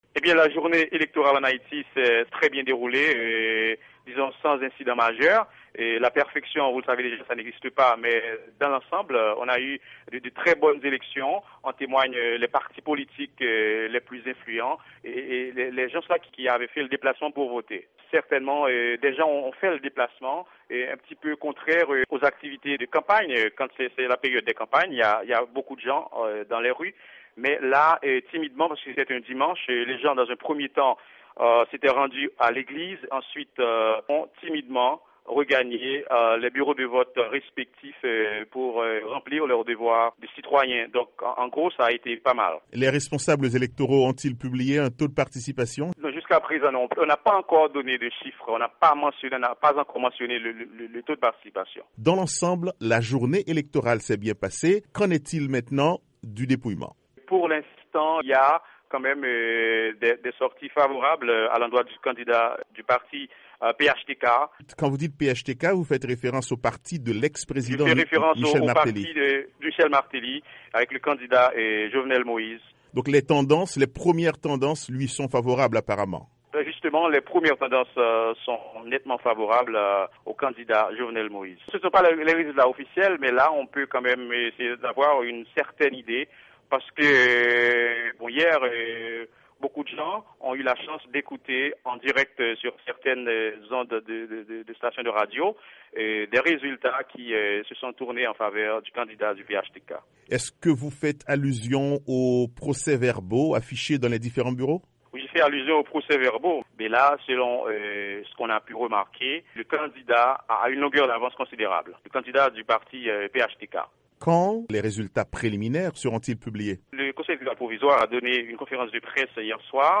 Le journaliste